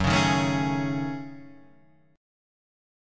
F# Minor 6th Add 9th